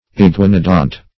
Search Result for " iguanodont" : The Collaborative International Dictionary of English v.0.48: Iguanodont \I*gua"no*dont\, a. (Paleon.) Like or pertaining to the genus Iguanodon.